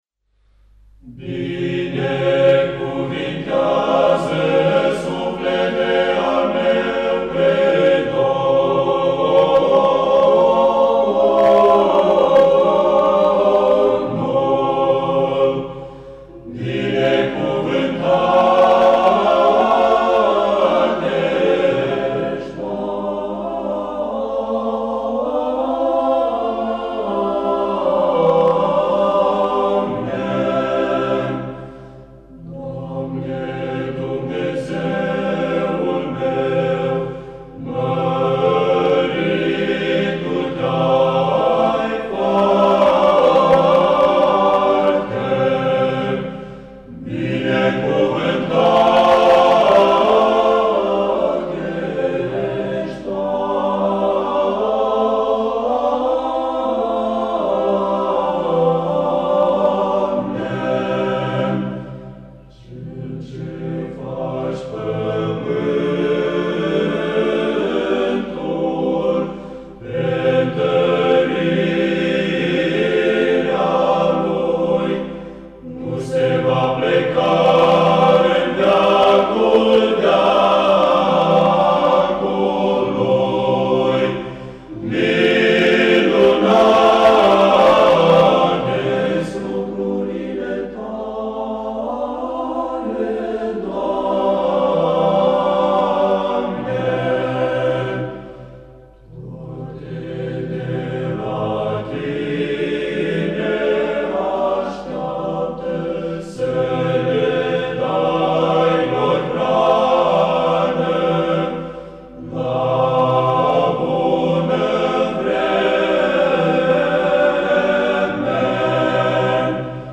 Antologie de cântări la strană
Interpretat de:    Corul seminarului şi al facultăţii